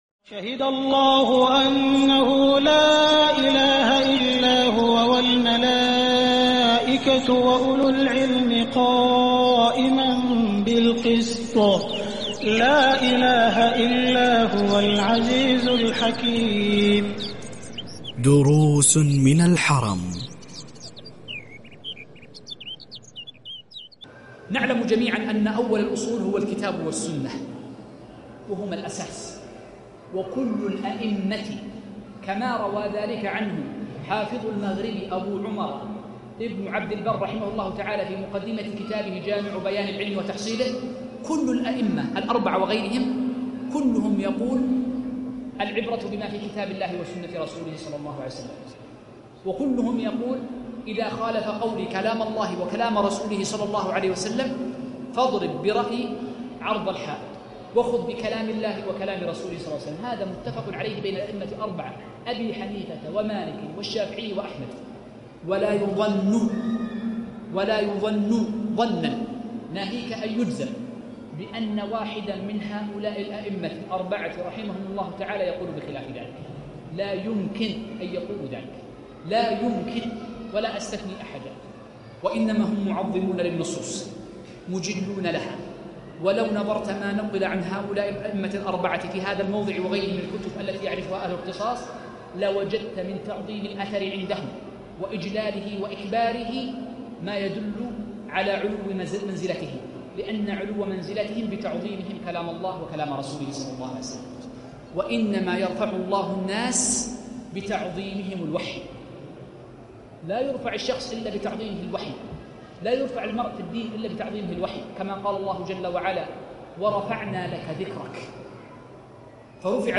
المدخل إلى مذهب الإمام أحمد - الدرس الأول ج2